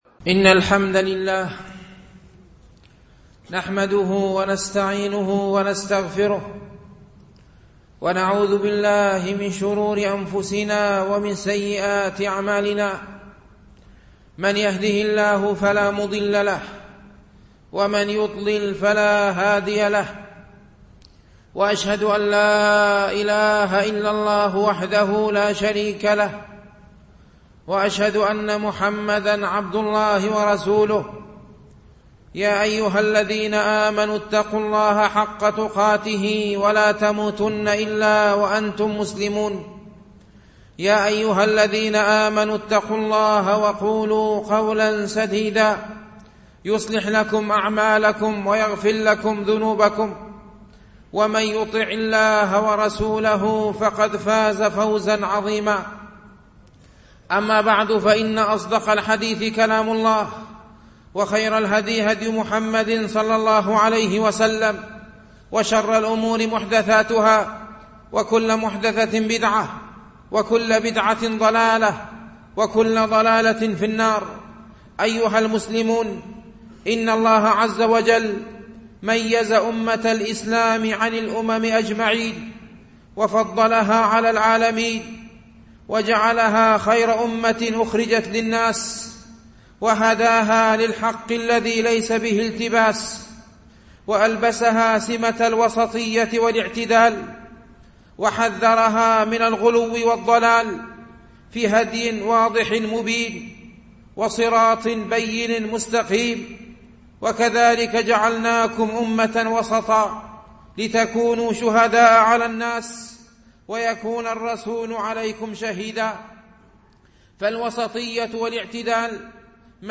خطبة بعنوان: الاعتدال